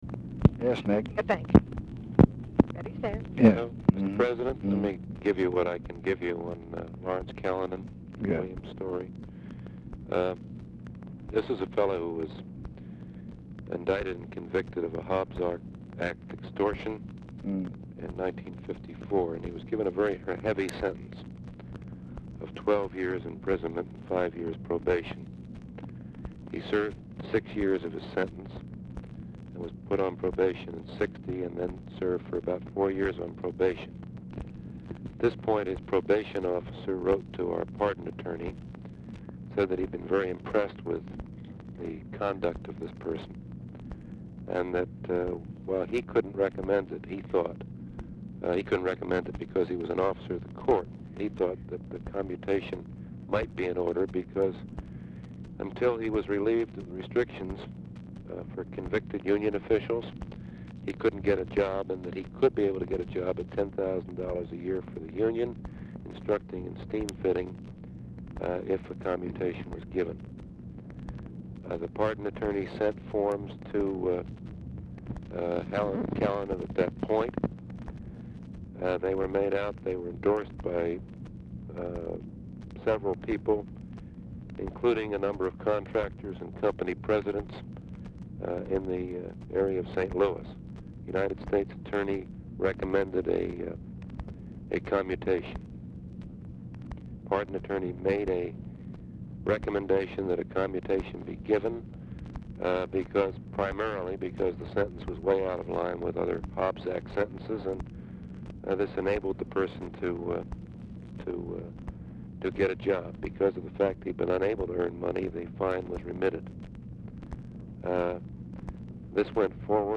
Telephone conversation # 9520, sound recording, LBJ and NICHOLAS KATZENBACH
LBJ PUTS KATZENBACH ON HOLD AT END OF CALL TO LOCATE BILL MOYERS AND TRANSFER KATZENBACH TO MOYERS
Format Dictation belt